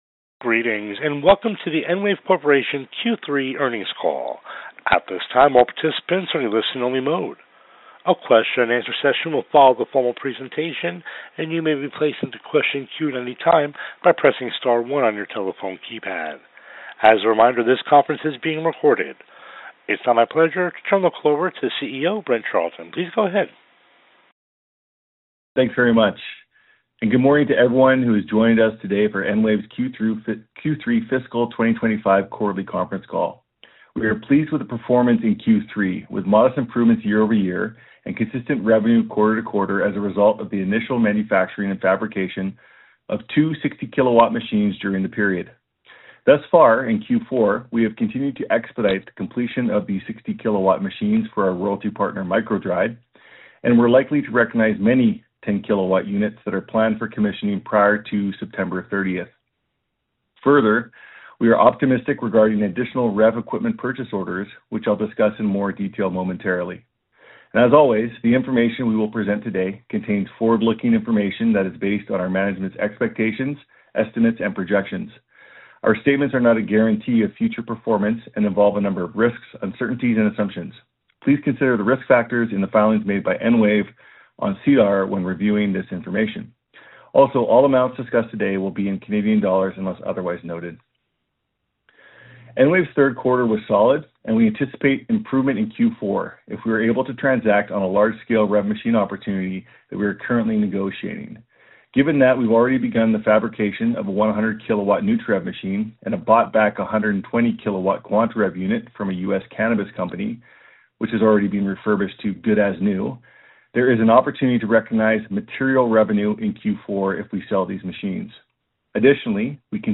Q1 2026 Earnings Call